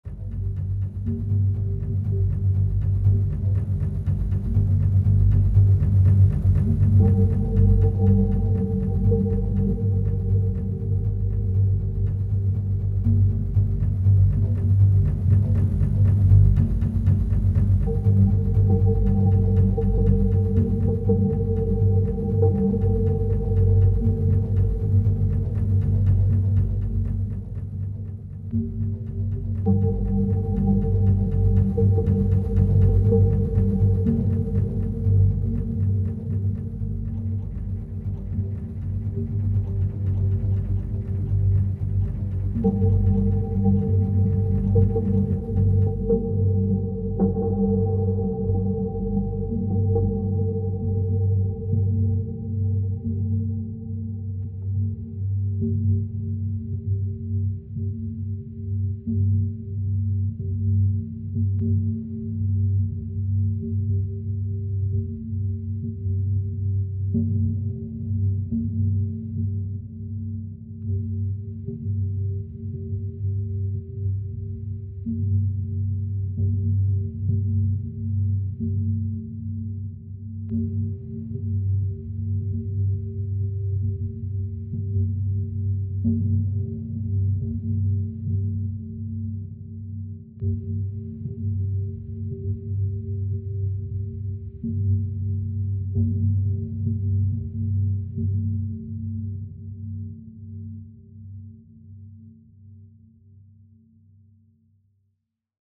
Melange of dark synths form a tense ambience.